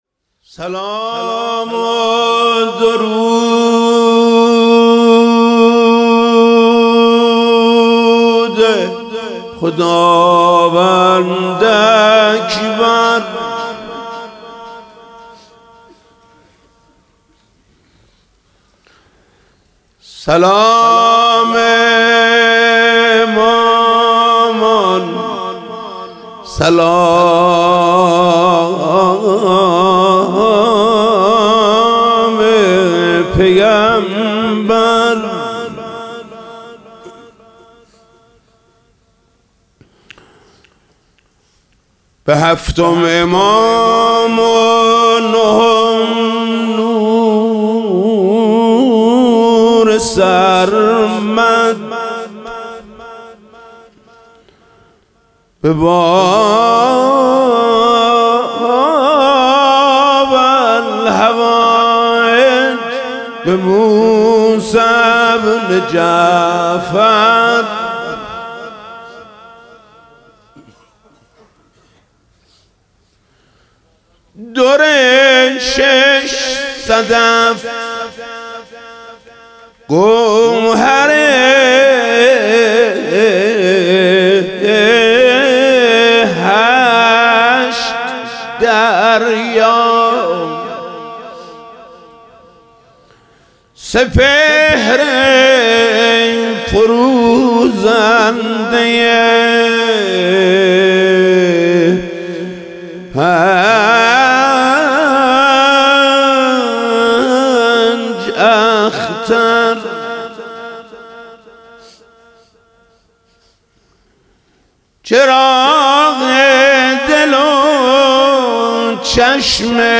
روضه: سلام و درود خداوند اکبر